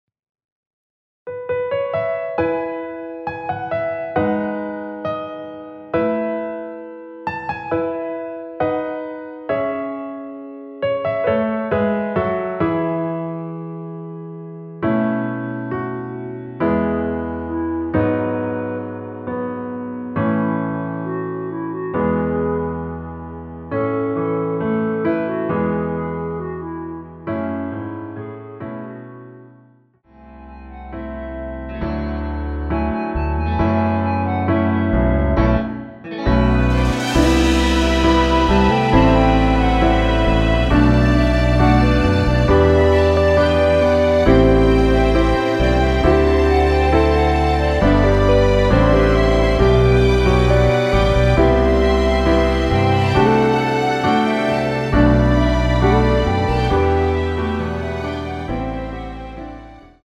원키에서(+2)올린 멜로디 포함된 MR입니다.(미리듣기 확인)
앞부분30초, 뒷부분30초씩 편집해서 올려 드리고 있습니다.
중간에 음이 끈어지고 다시 나오는 이유는